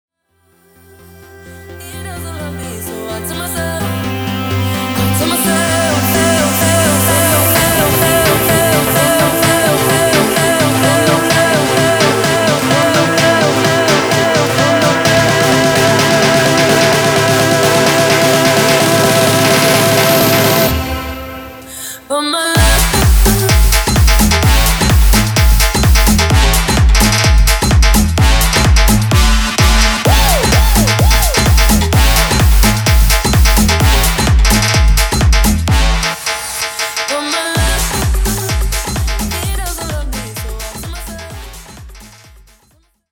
Reggaeton dj remixes
DJ